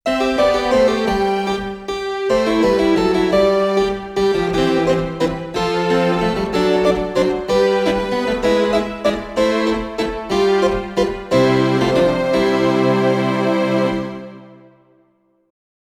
Jeweils zwei Sounds lassen sich übereinander spielen als Layer oder zwischen linker und rechter Hand aufteilen als Split.
Layer: Harpsichord 2 + Concert Strings
yamaha_csp-170_test_demo03_layer_cembalo_strings.mp3